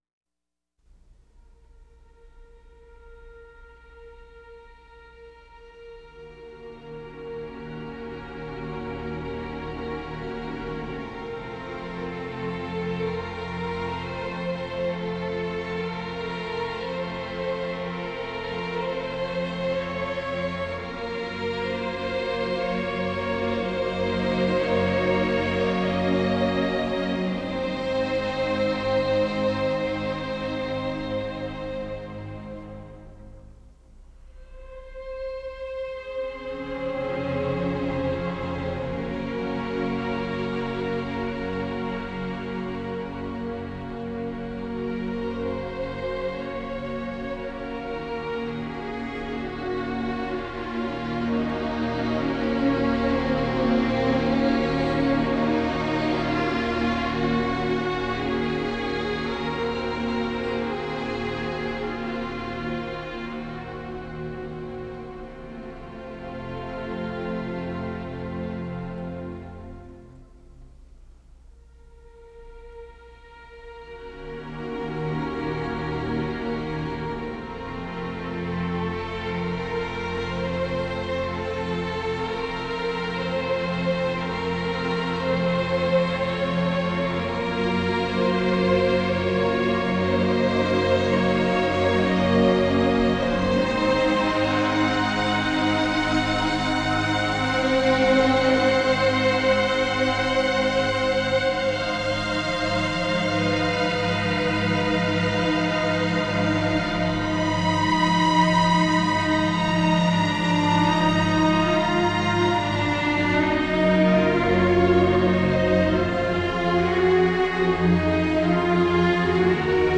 4-05-adagio-for-strings-op-11.m4a